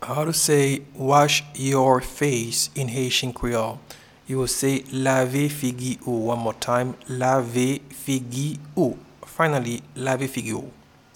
Pronunciation and Transcript:
Wash-your-face-in-Haitian-Creole-Lave-figi-ou.mp3